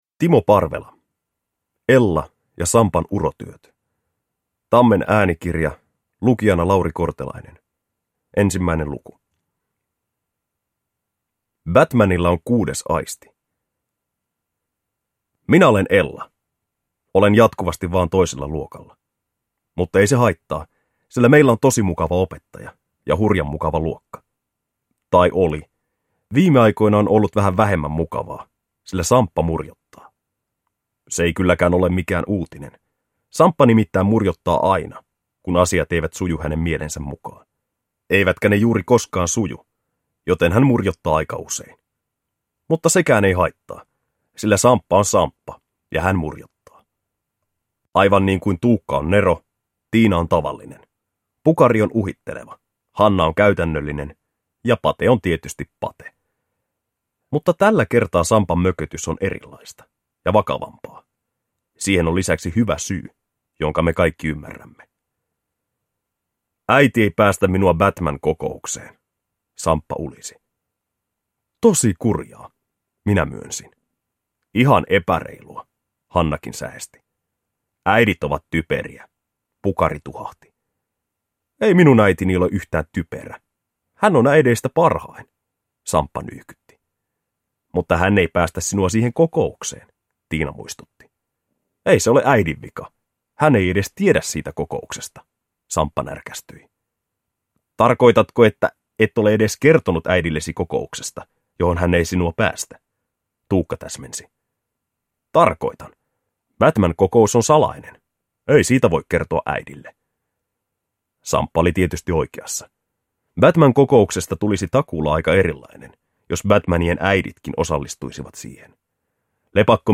Ella ja Sampan urotyöt – Ljudbok